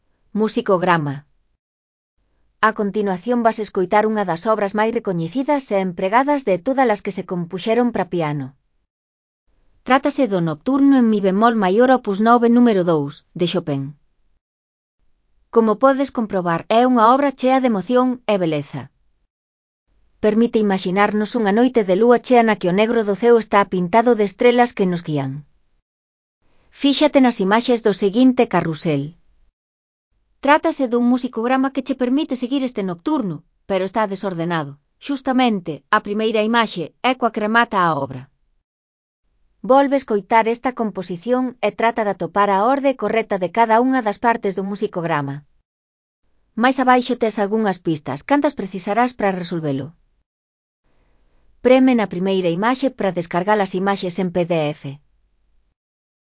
Escribíronse moitas pezas musicais para piano.
É unha obra chea de emoción e beleza.